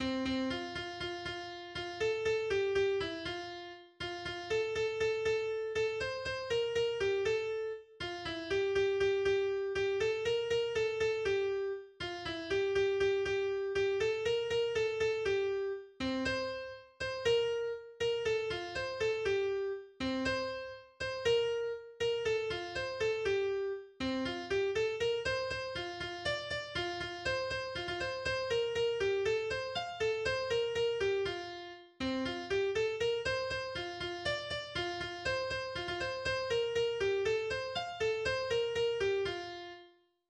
tyroler Volks- und Spottlied